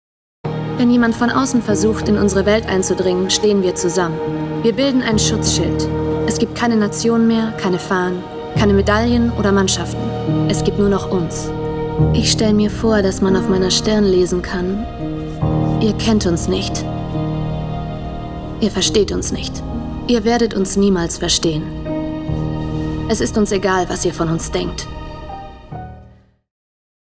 Fränkisch
Trailerausschnitt Serie